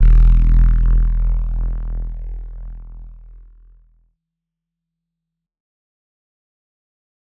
Bass_E_02.wav